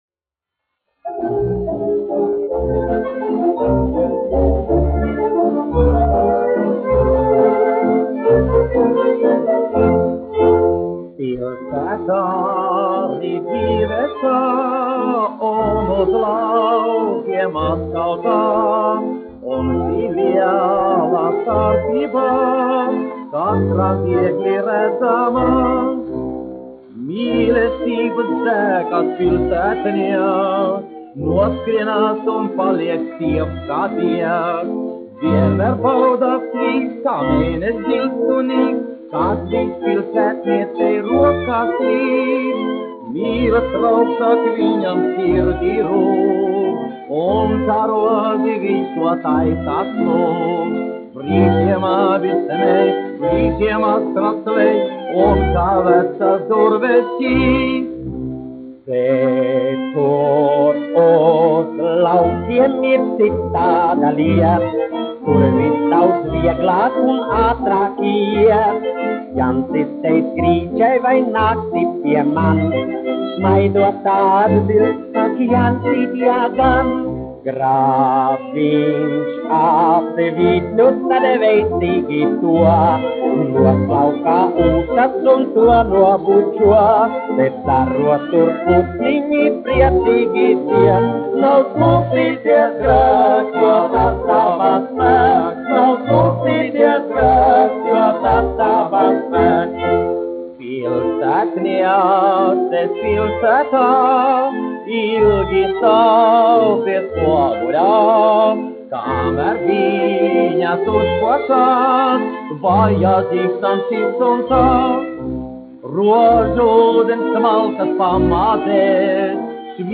1 skpl. : analogs, 78 apgr/min, mono ; 25 cm
Populārā mūzika
Humoristiskās dziesmas
Skaņuplate